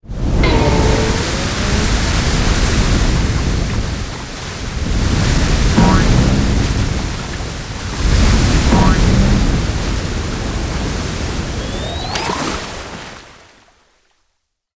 OZ_Geyser.ogg